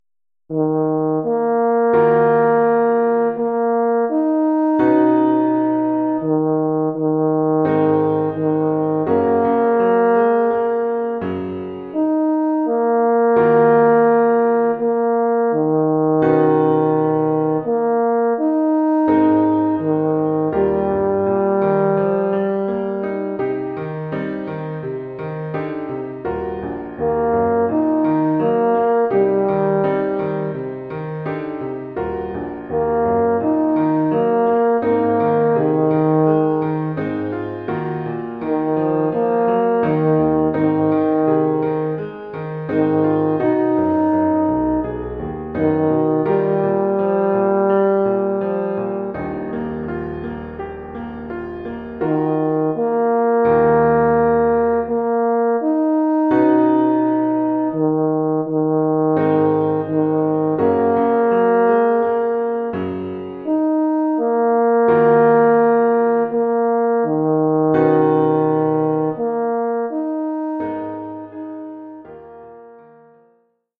Formule instrumentale : Cor naturel mib et piano
Oeuvre pour cor naturel mib et piano.